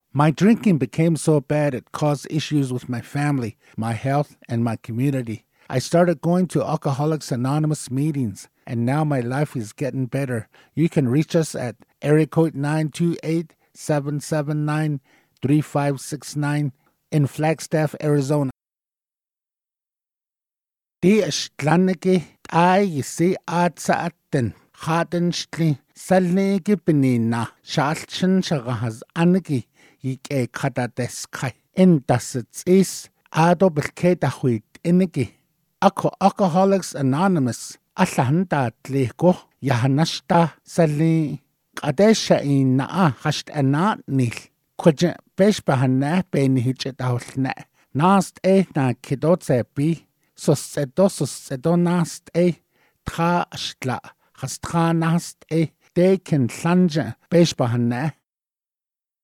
Navajo Translated PSA Audio
Flagstaff-English_Navajo_Combo.mp3